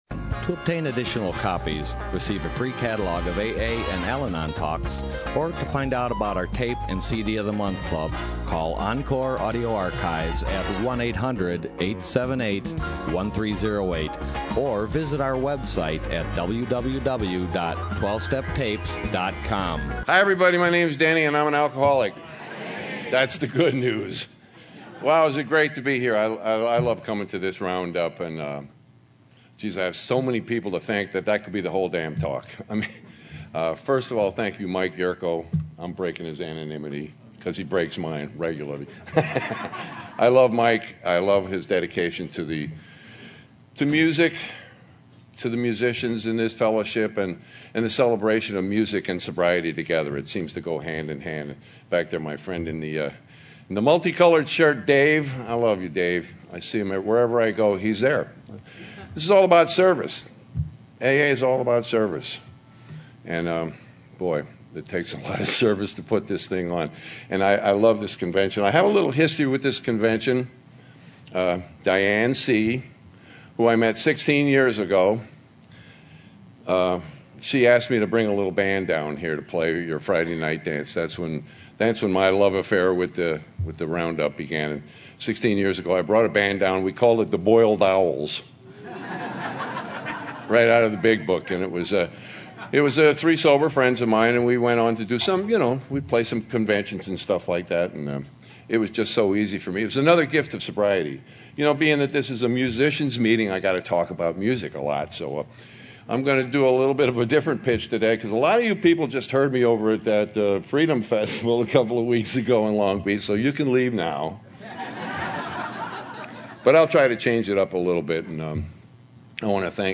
SOUTHBAY ROUNDUP 2012